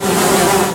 flies.ogg.mp3